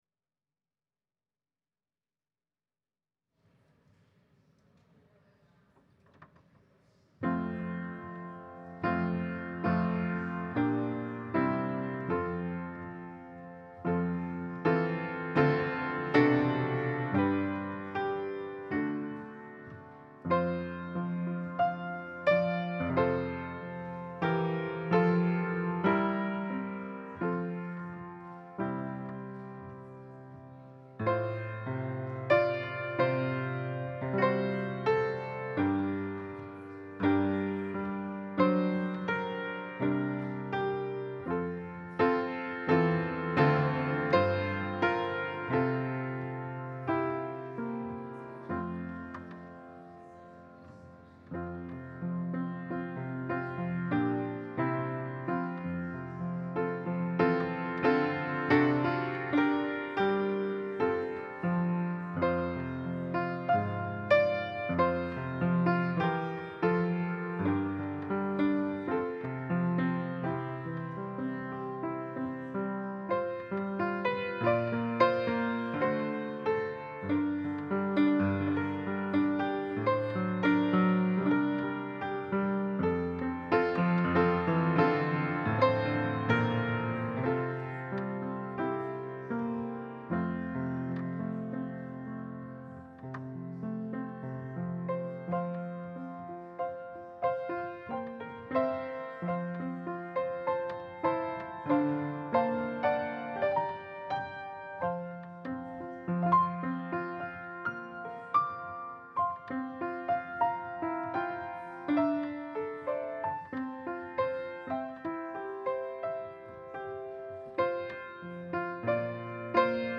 Passage: Luke 24: 13-35 Service Type: Sunday Service Scriptures and sermon from St. John’s Presbyterian Church on Sunday